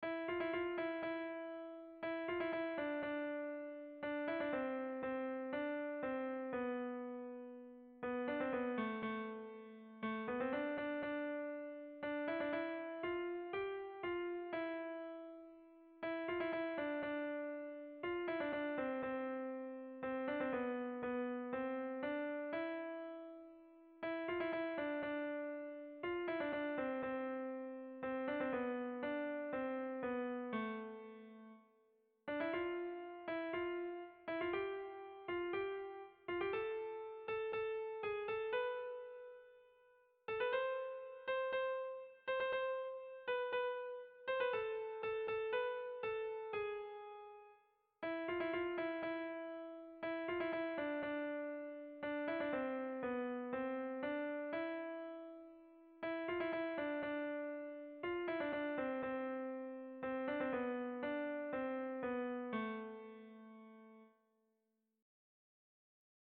Kontakizunezkoa
Hamaseiko handia (hg) / Zortzi puntuko handia (ip)
A-B-C-D